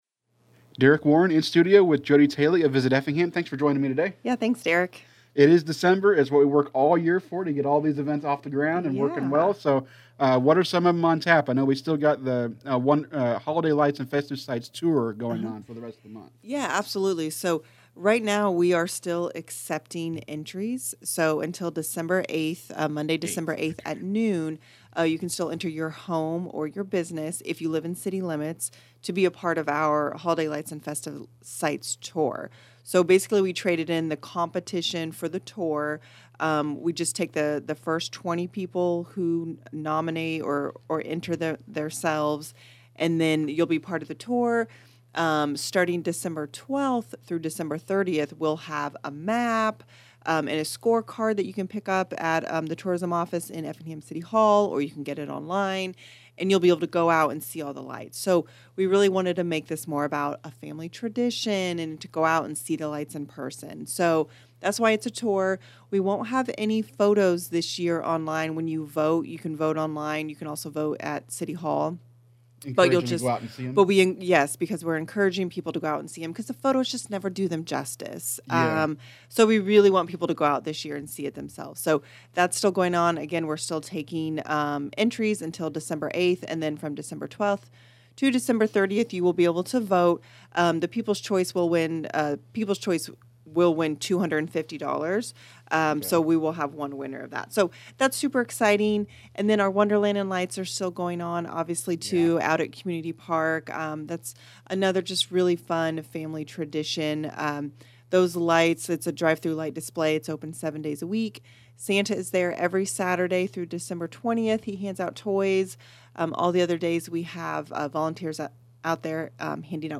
This is part of a monthly series of interviews that will continue next month.